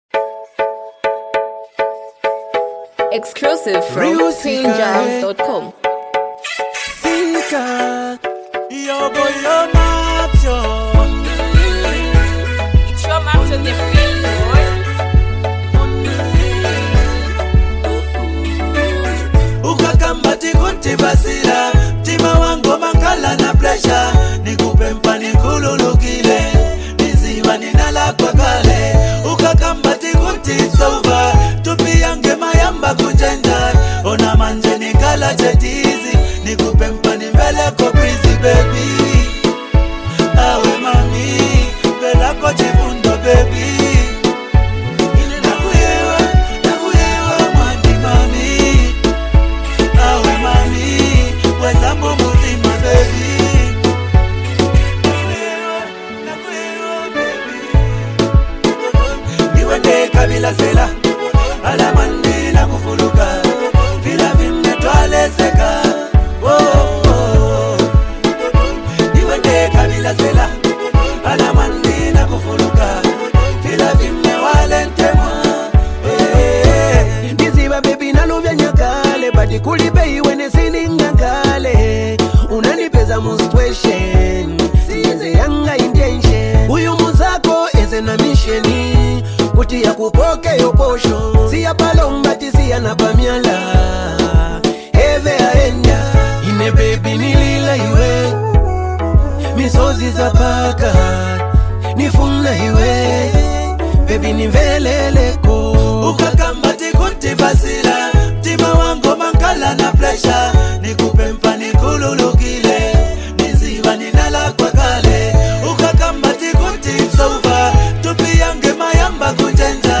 hype, street vibes, and a powerful hook
unique singing flow